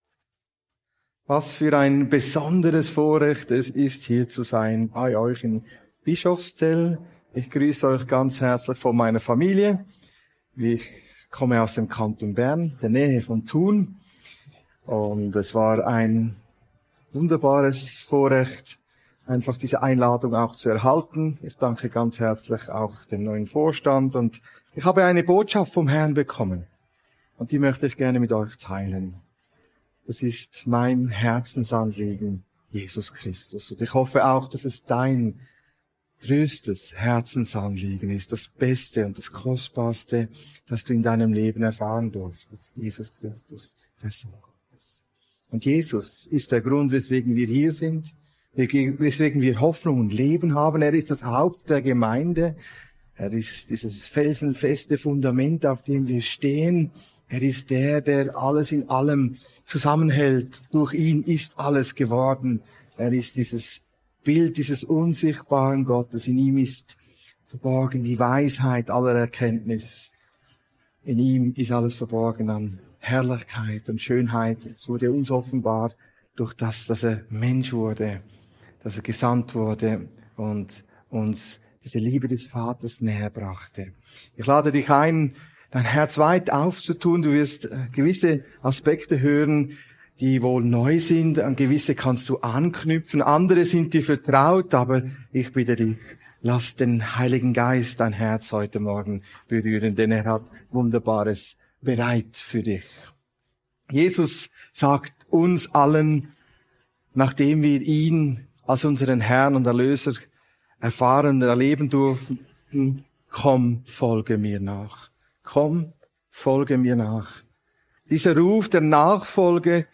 Link zur Predigt mit Power-Point